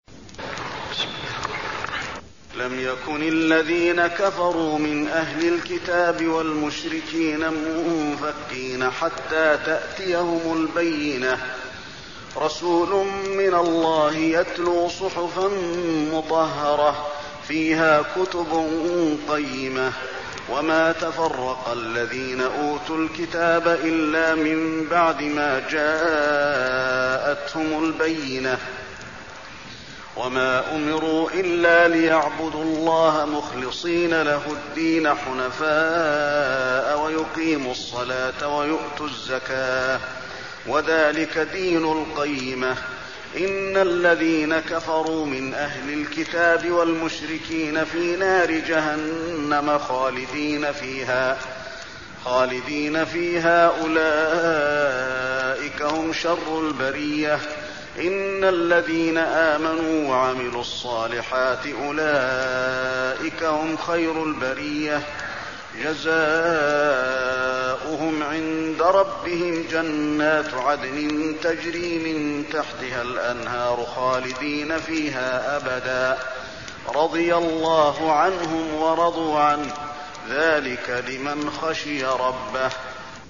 المكان: المسجد النبوي البينة